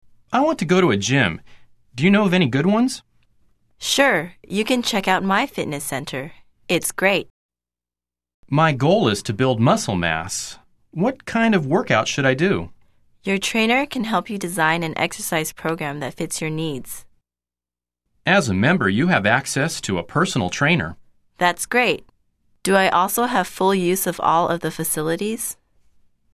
來聽老美怎麼說？